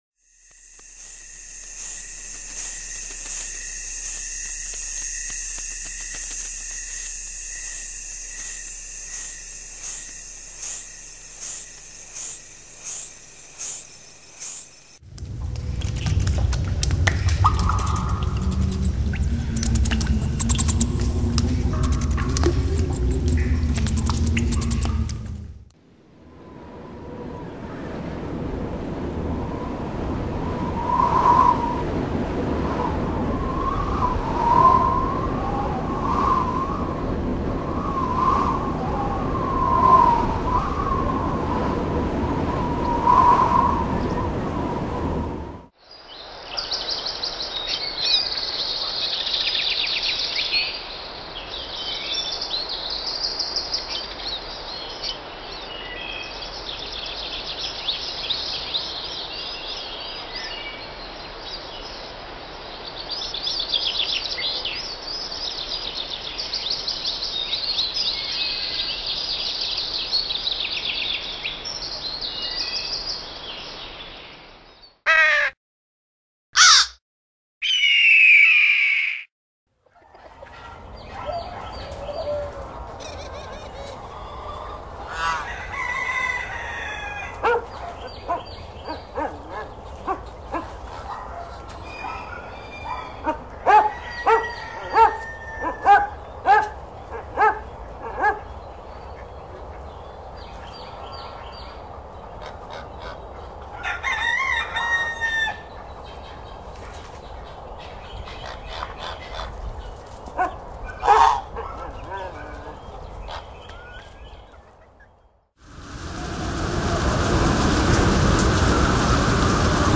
Nature-Sounds-1.mp3